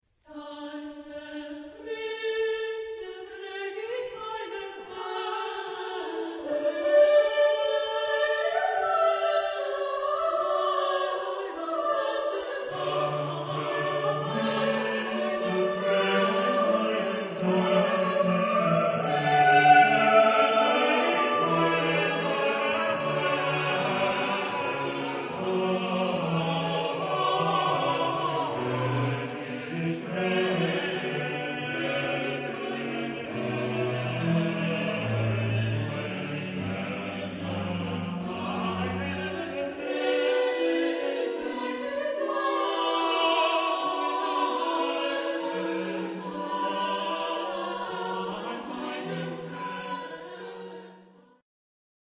Genre-Style-Forme : Sacré ; Motet ; Psaume
Type de choeur : SATB  (4 voix mixtes )
Tonalité : ré majeur